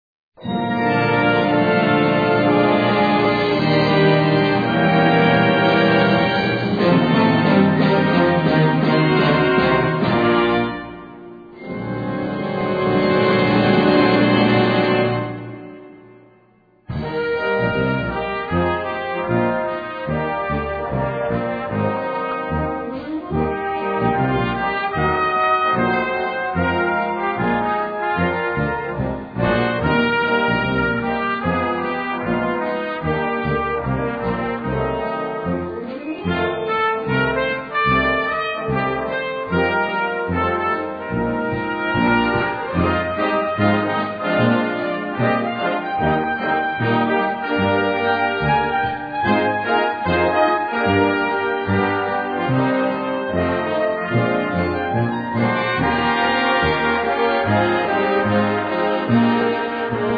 Gattung: Potpourri
Besetzung: Blasorchester